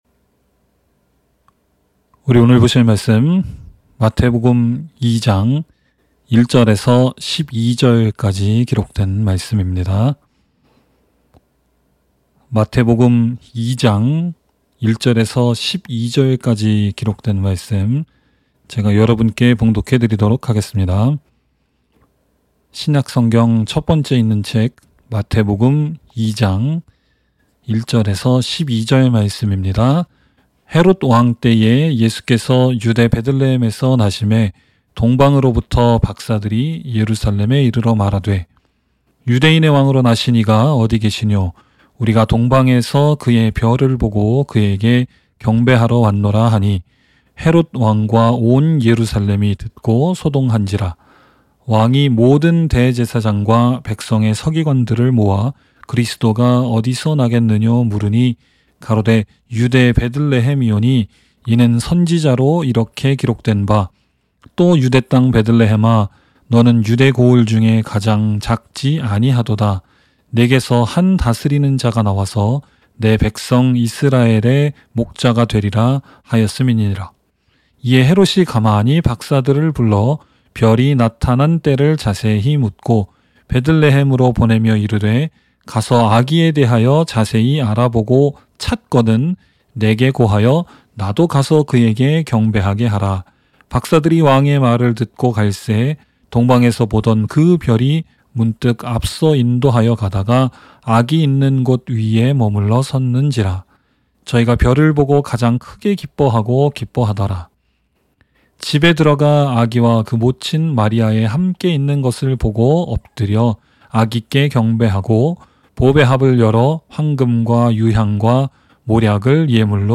by admin-new | Dec 19, 2021 | 설교 | 0 comments